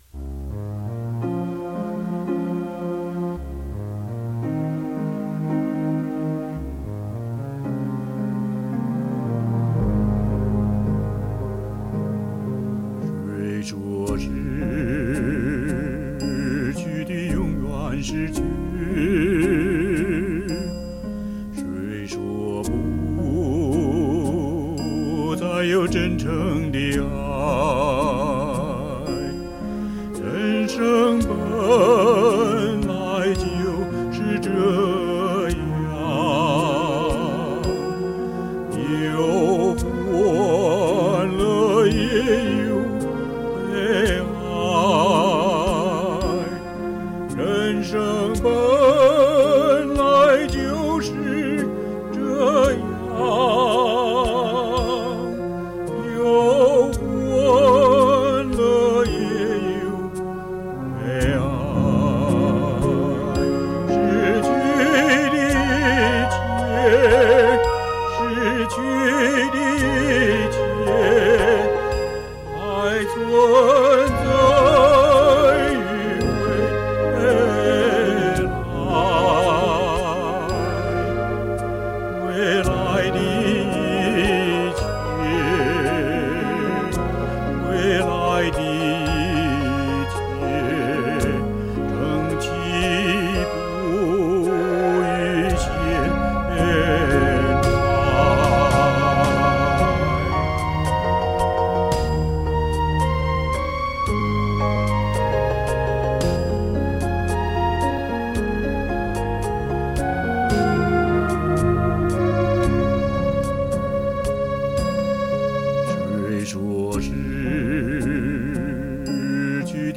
今天，发出这首由我重唱的歌祝大家节日快乐。